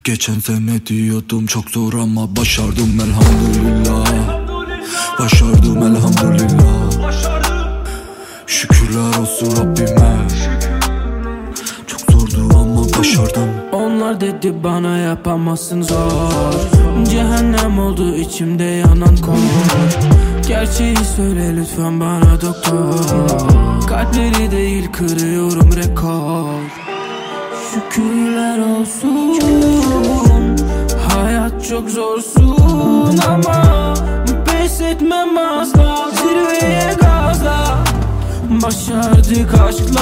Kategori Rap